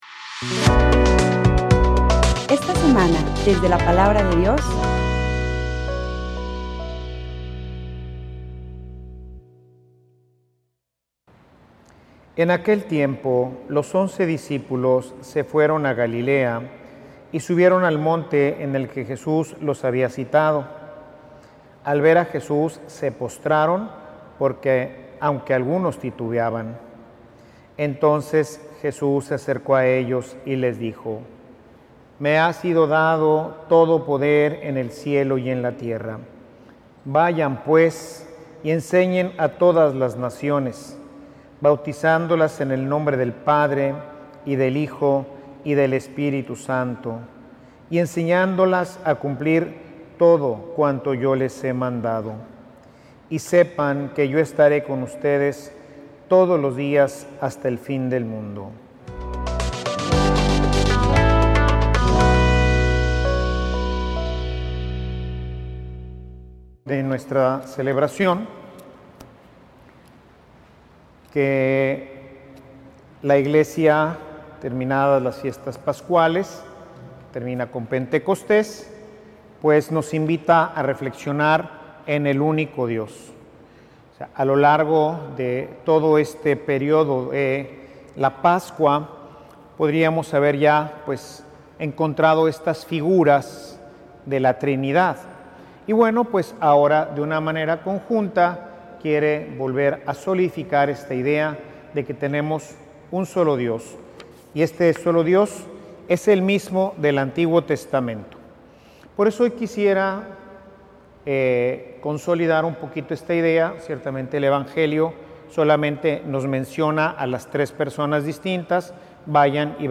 Homilia_El_ateismo_moderno.mp3